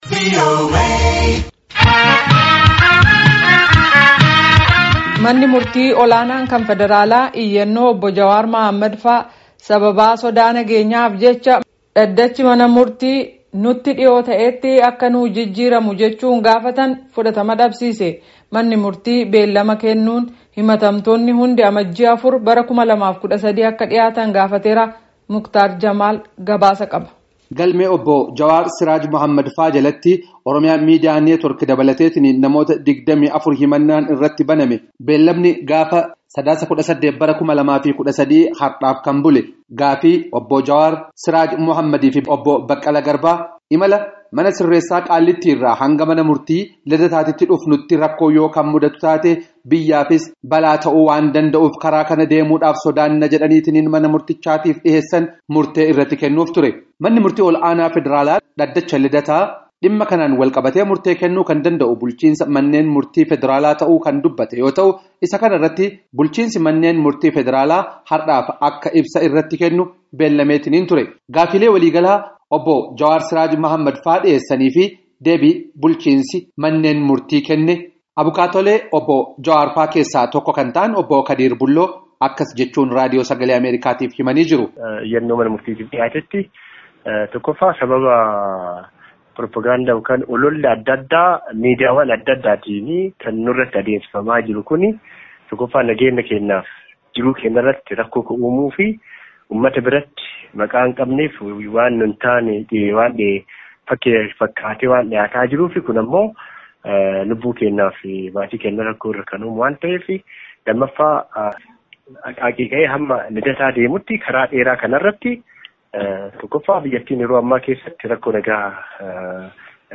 Gabaasa